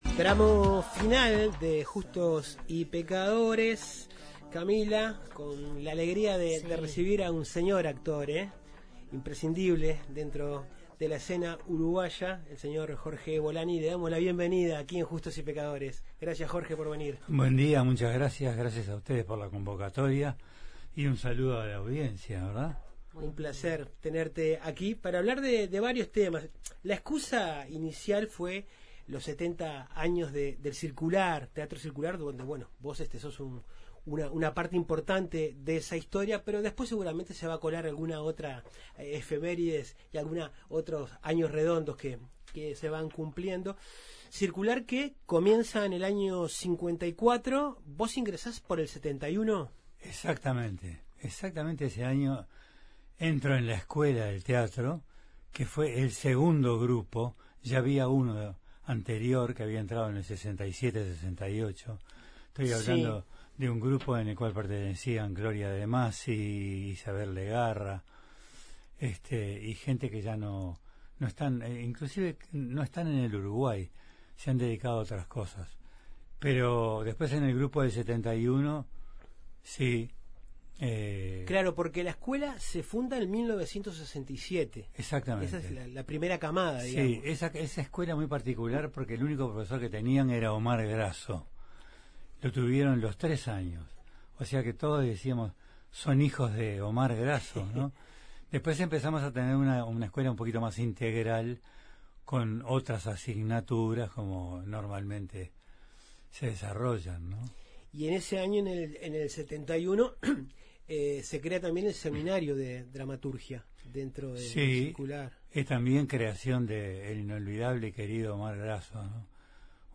En Justos y pecadores recibimos al reconocido actor
El 70º aniversario del Teatro Circular fue la excusa para conversar con el actor y director Jorge Bolani sobre varios aspectos de su carrera artística.